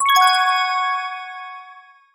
короткие